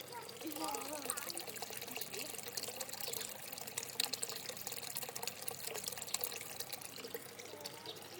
Plätschern